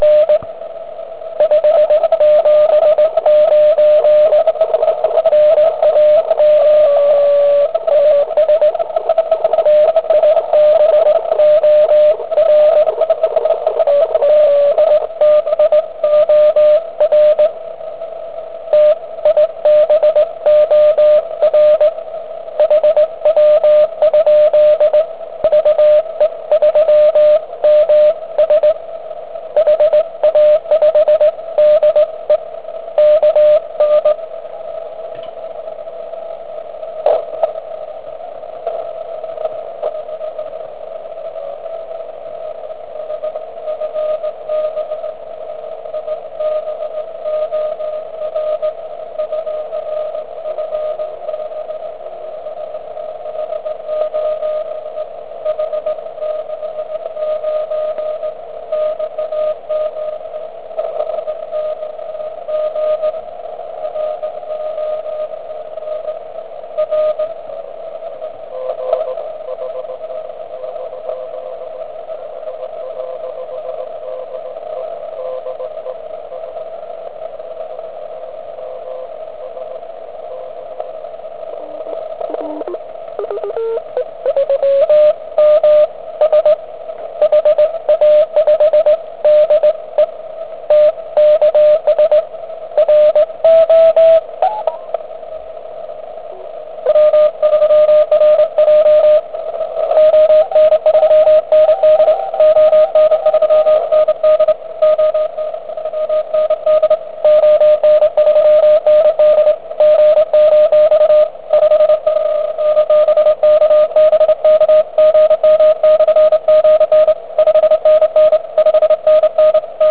Ta komprese je tak účinná, že se blbě dávají reporty.
Je to skutečně nádherně vyrovnané a hlavně s velice rychlou konstantou tohoto AVC.
Ale pojďme si to demonstrovat na ukázkách poslechu DX stanic v pásmu 80m.
Přitom poslech je naprosto vyrovnaný.
DX spojení ATOS (*.wav 850 KB)